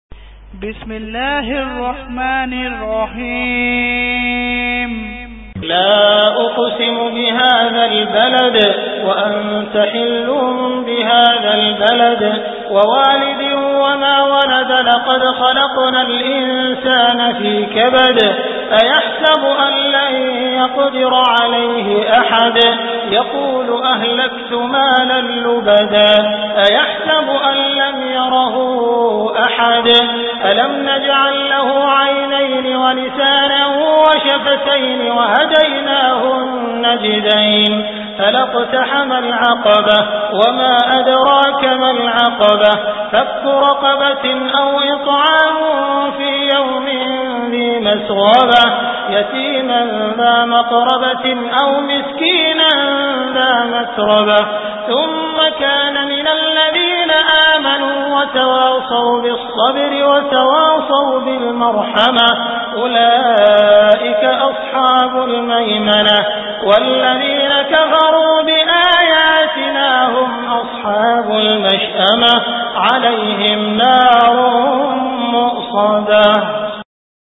Surah Al Balad Beautiful Recitation MP3 Download By Abdul Rahman Al Sudais in best audio quality.